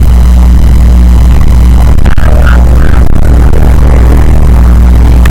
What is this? Tags: meme, soundboard